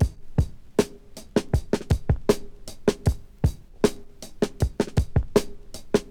• 78 Bpm High Quality Drum Groove B Key.wav
Free breakbeat sample - kick tuned to the B note. Loudest frequency: 947Hz
78-bpm-high-quality-drum-groove-b-key-vGi.wav